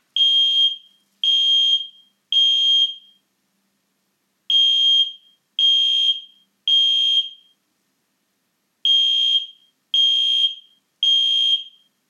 • Ei208iDW Alarm:
ei208idw-kohlenmonoxidmelder-alarm.mp3